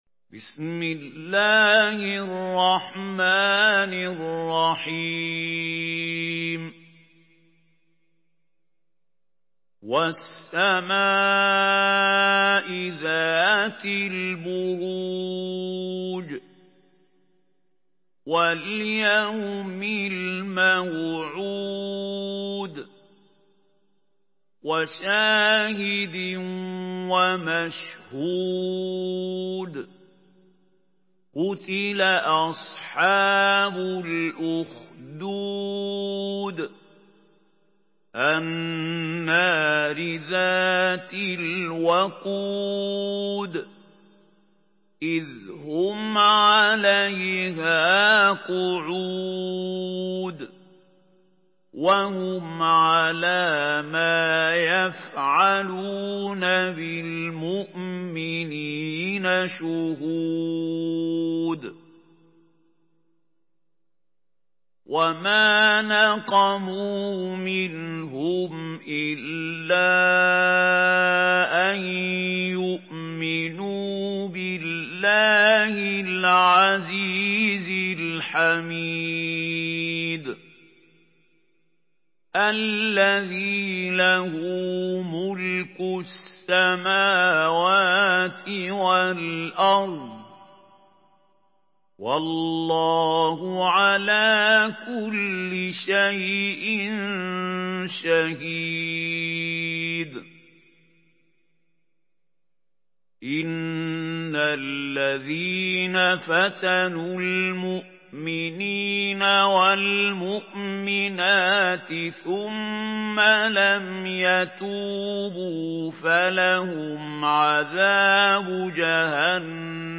Surah Büruc MP3 in the Voice of Mahmoud Khalil Al-Hussary in Hafs Narration
Murattal